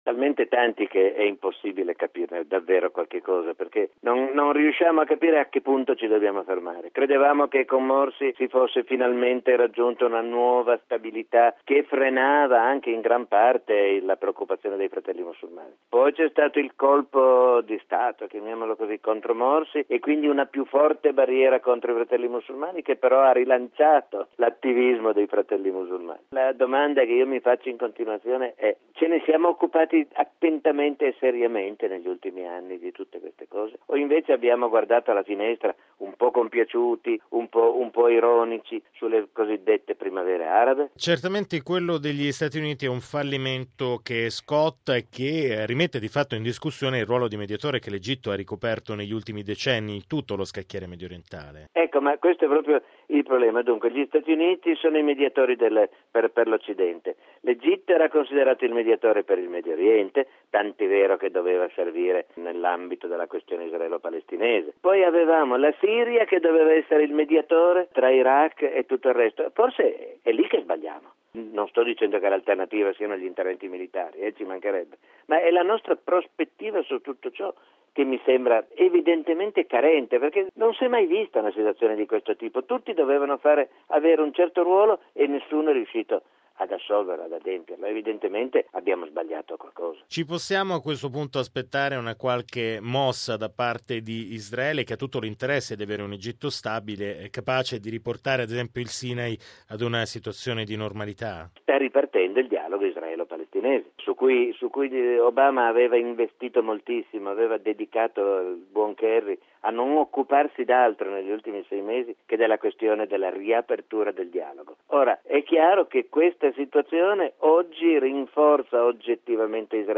Radiogiornale del 07/08/2013 - Radio Vaticana